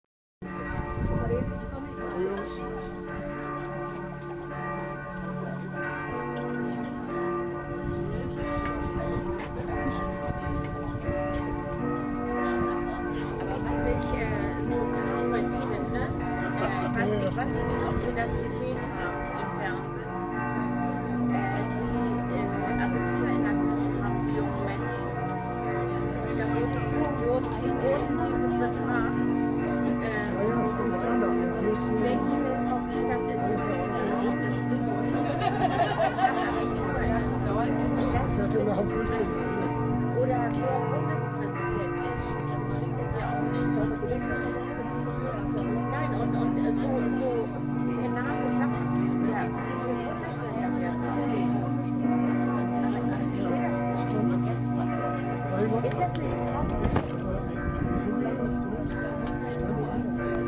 Zum Mühlentag hält er traditionell einen Freiluftgottesdienst ab.
Glockengelaeut.mp3